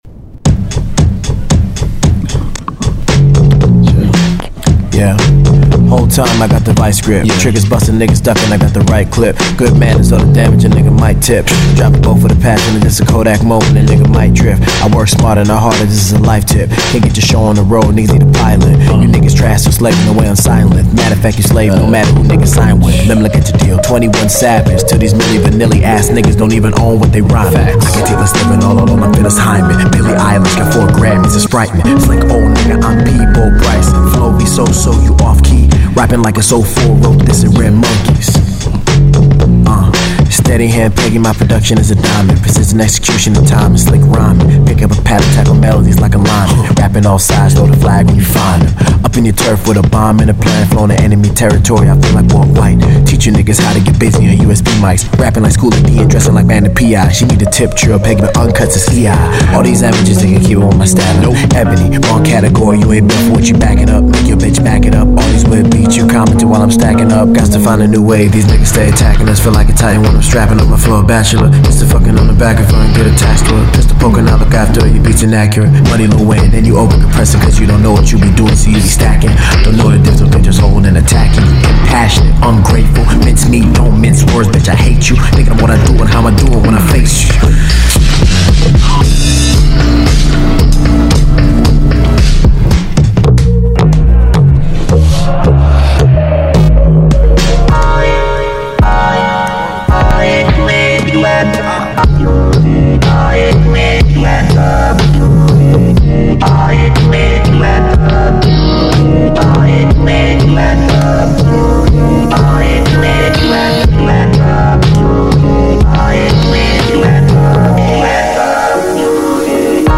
This has been a year unlike any in the history of hip-hop.